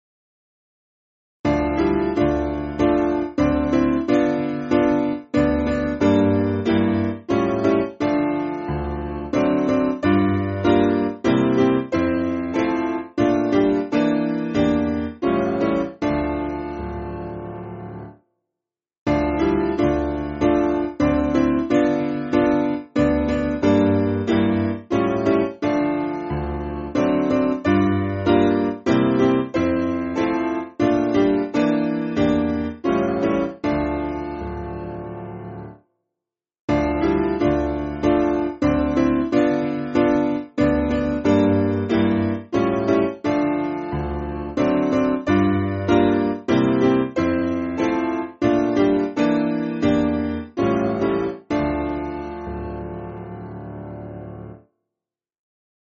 Simple Piano
(CM)   3/Dm